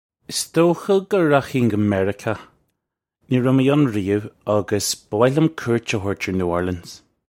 Iss doe-kha guh rakh-hin guh Merri-kaw. Nee rev may un ree-umh ukh buh woh lyum koo-irt uh howert urr New Orleans
This is an approximate phonetic pronunciation of the phrase.